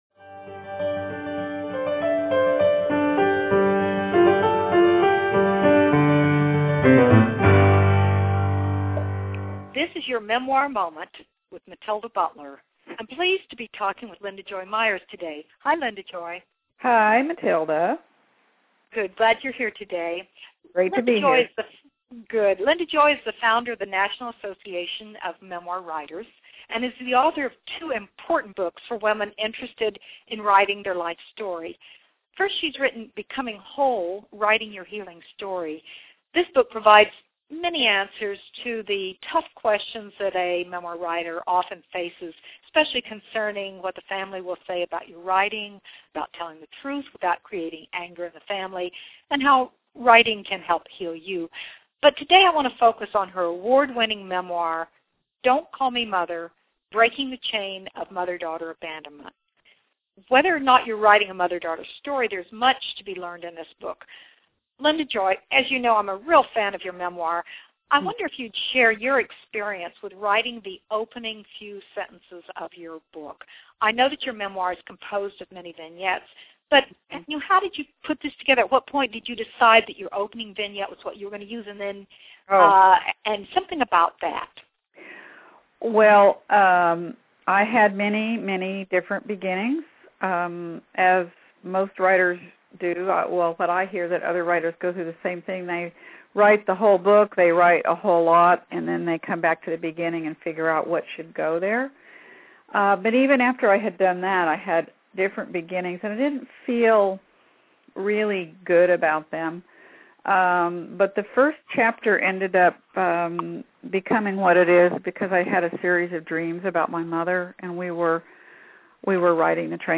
Enjoy this interview?